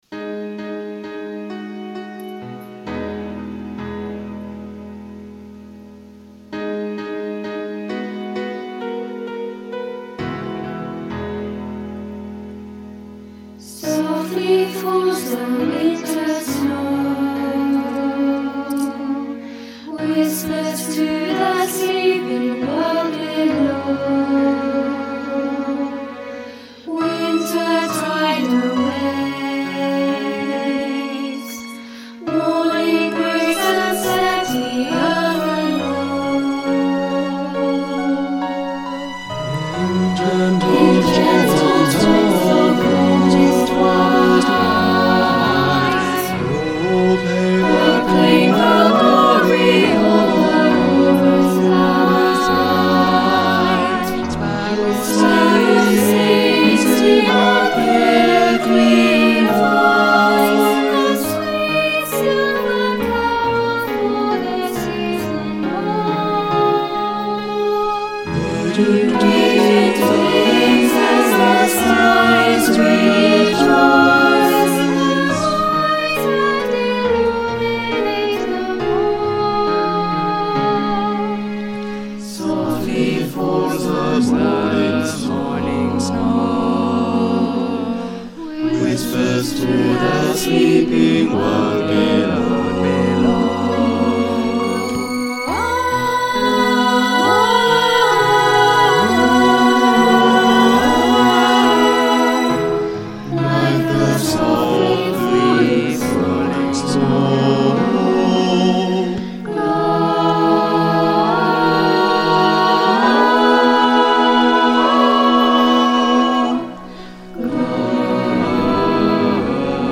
Chamber Choir - Glow
Virtual!!